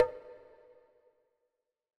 KIN Conga 2.wav